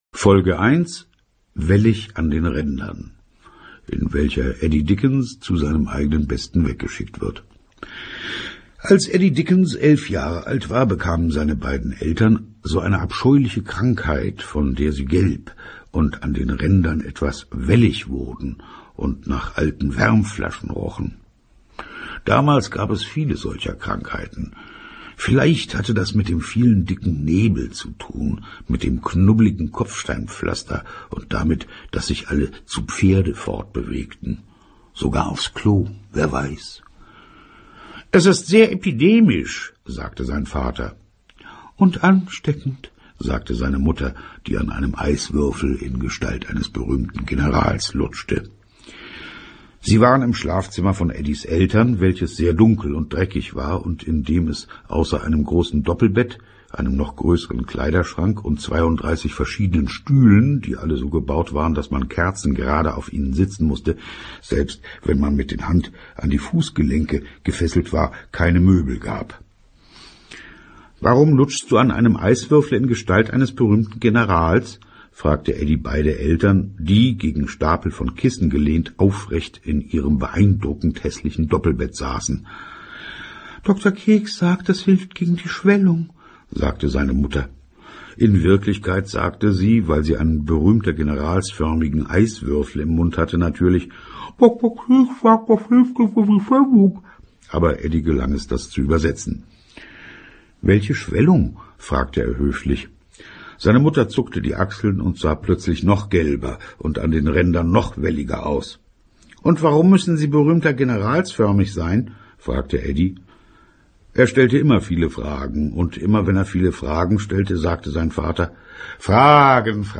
Harry Rowohlt (Sprecher)
Ungekürzte Lesung